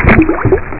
SPLASH.mp3